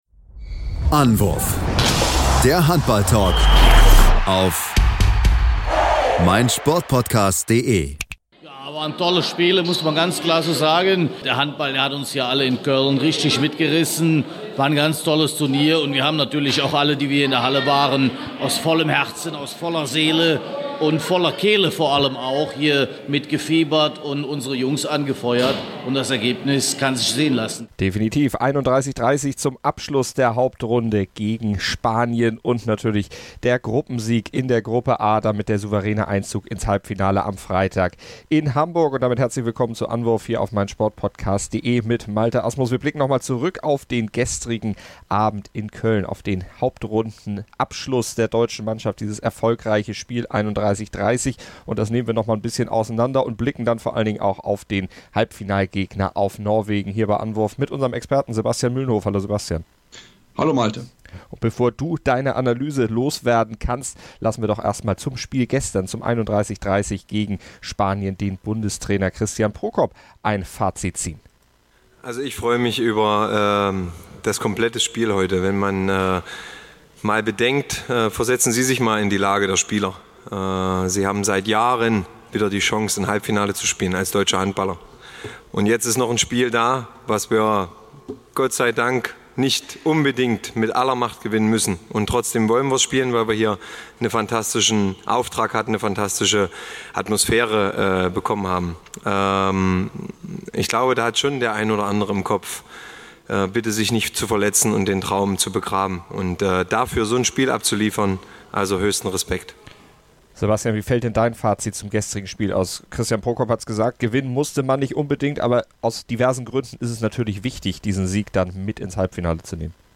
Interviews
Steffen Fäth und auch der Bundestrainer Christian Prokop äußert sich bei uns am Mikro zum Spiel und dem nächsten Gegner.